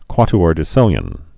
(kwŏt-ôr-dĭ-sĭlyən, kwŏty-)